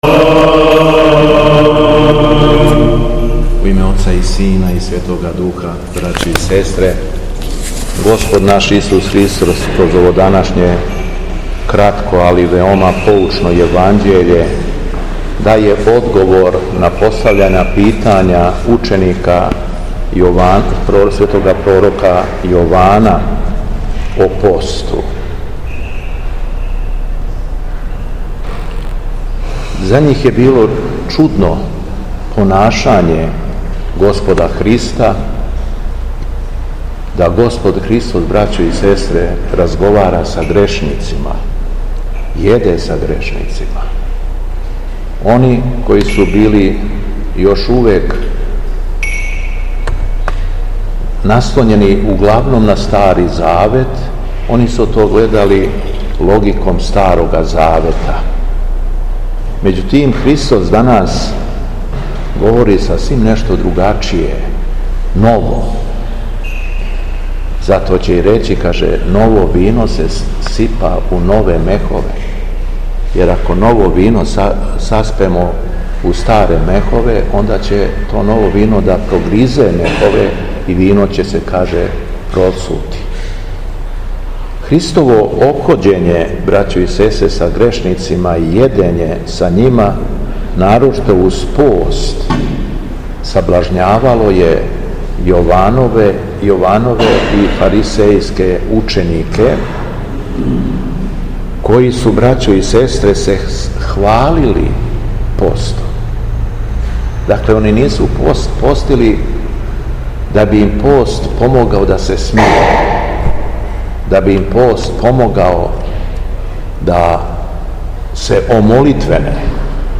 У петак, 20. јуна 2025. године, када наша Света Православна Црква прославља Светог свештеномученика Теодора Анкирског, Његово Високопреосвештенство Митрополит шумадијски г. Јован служио је Свету Архијерејску Литургију у храму Свете Петке у крагујевачком насељу Виногради уз саслужење братства овога с...
Беседа Његовог Високопреосвештенства Митрополита шумадијског г. Јована
После прочитаног јеванђелског зачала Високопреосвећени Митрополит се обратио беседом сабраном народу рекавши: